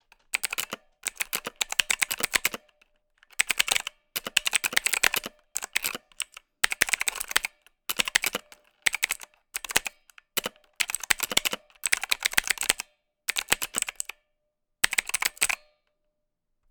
Keyboard, computer, mechanical, typing, quickly, rapidly, keys, press, button, click, tap_96Khz_Mono_ZoomH4n_NT5-002
button click computer key keyboard keypress press sound effect free sound royalty free Sound Effects